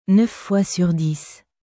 Neuf fois sur dixヌァフ フォワ スュール ディス